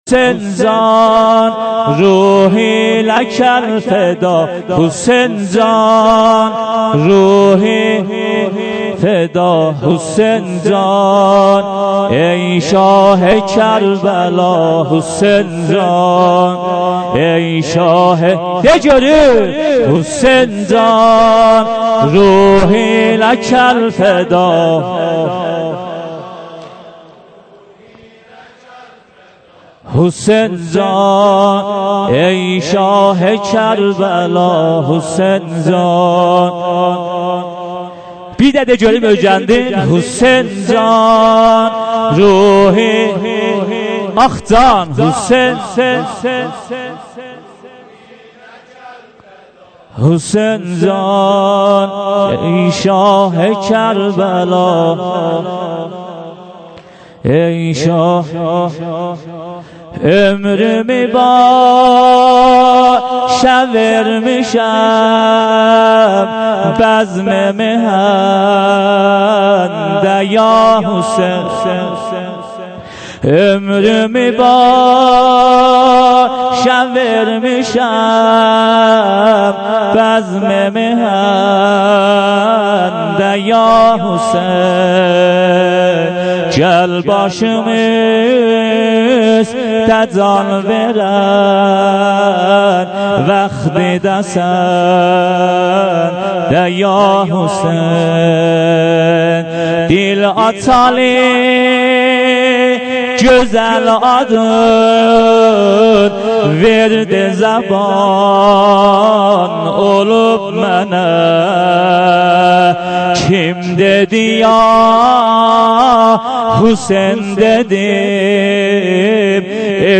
محرم 97 - شب پنجم - بخش دوم سینه زنی